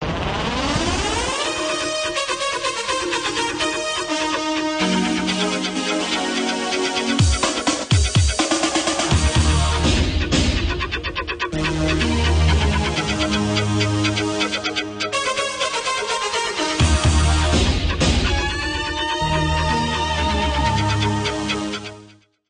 Да, фрагмент явно из телевизора, но я не могу вспомнить откуда)